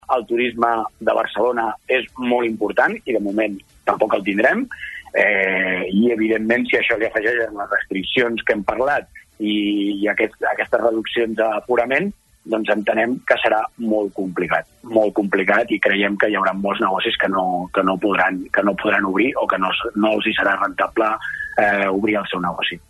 BegurEntrevistes Supermatí